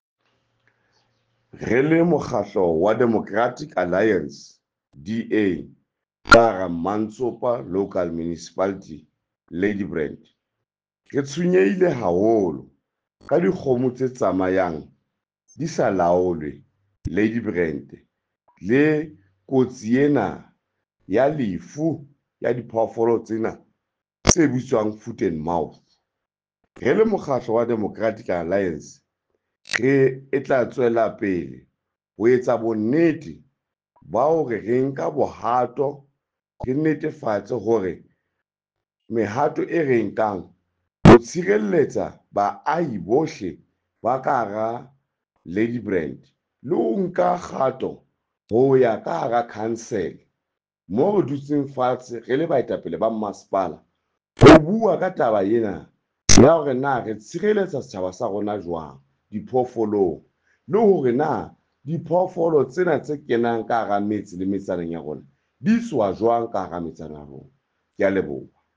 Sesotho soundbite by Cllr Nicky van Wyk.